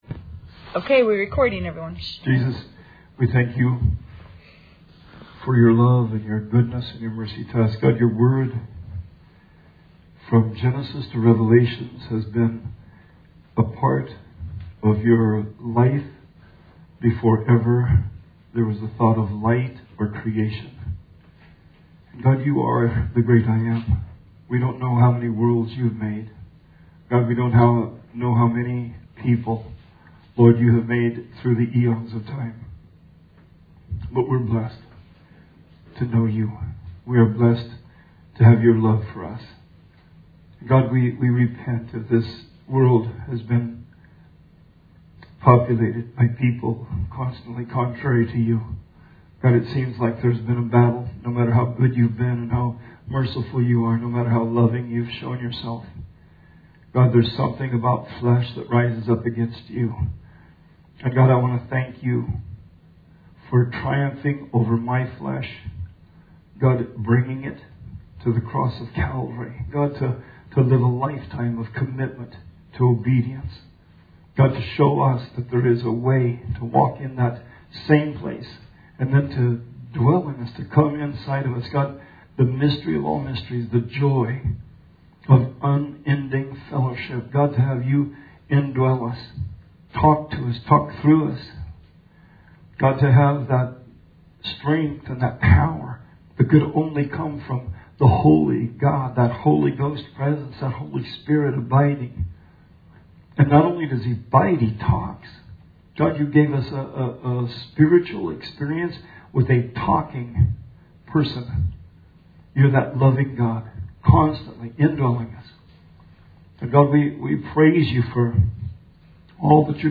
Bible Study 7/8/20